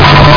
chime1.mp3